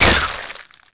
sound / monsters / insect / deathm.wav
1 channel
deathm.wav